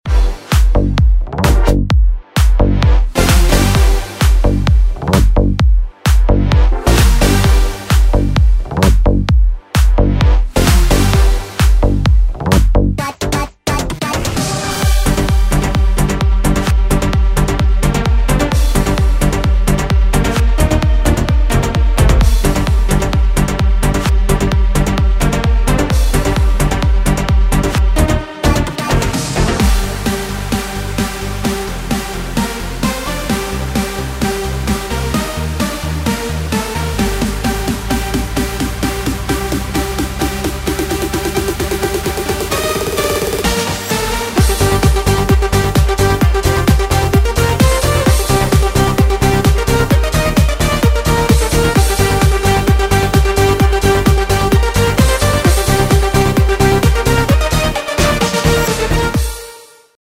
ז'אנרDance
BPM130